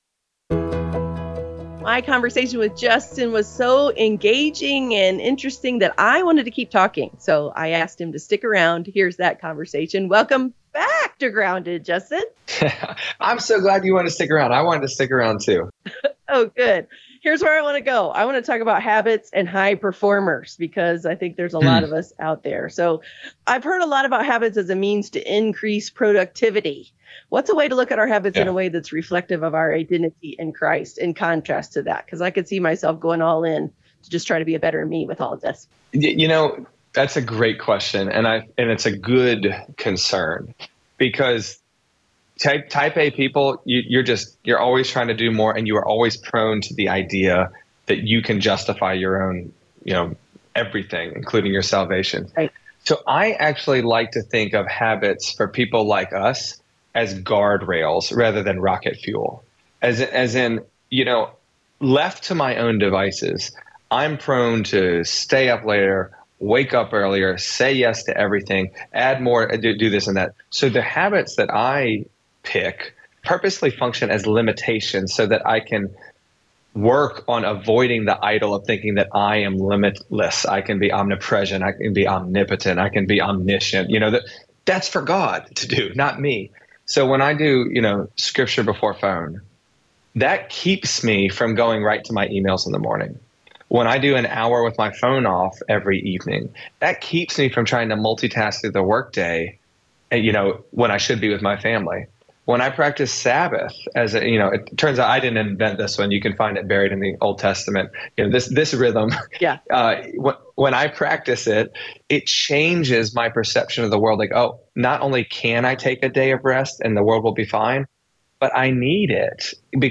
After the live program ended, the conversation continued!